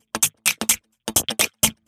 Electrohouse Loop 128 BPM (33).wav